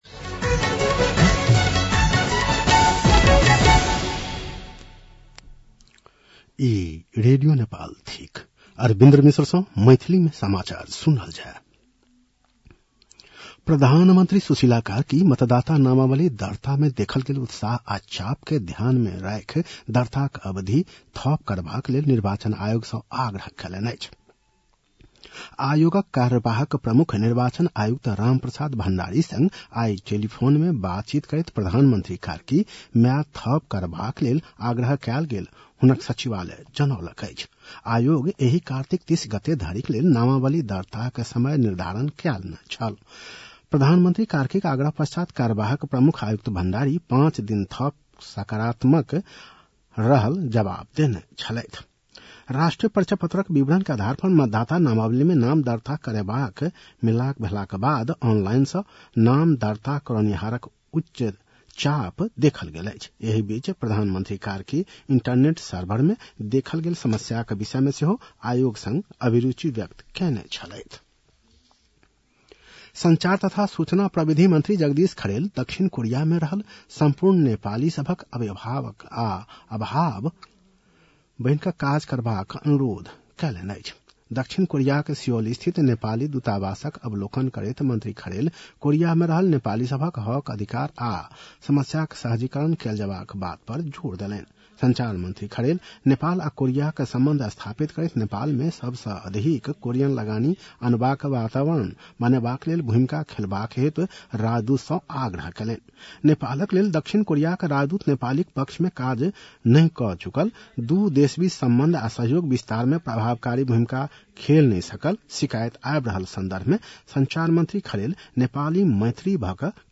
मैथिली भाषामा समाचार : २८ कार्तिक , २०८२